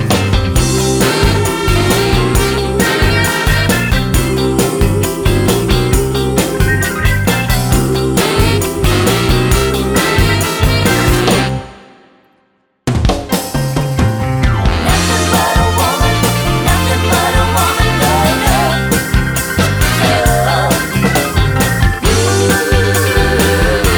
No Saxophone Solo Pop (1960s) 3:09 Buy £1.50